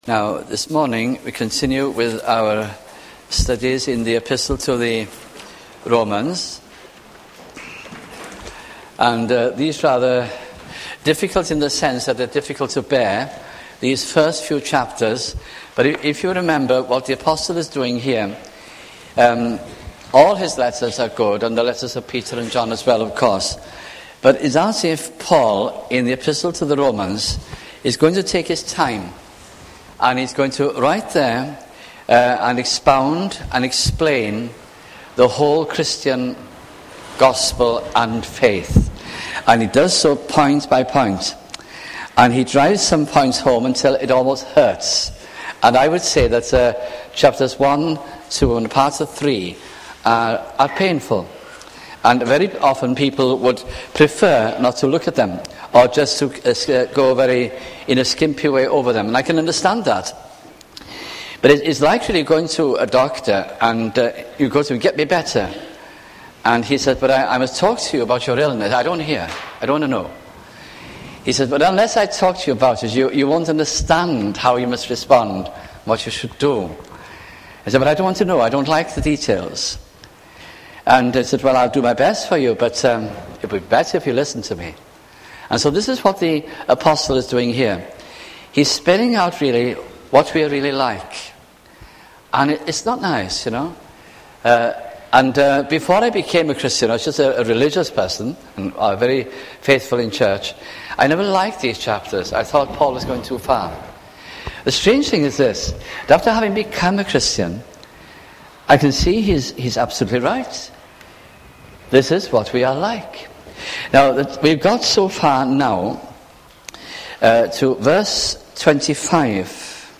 » Romans 1996-98 » sunday morning messages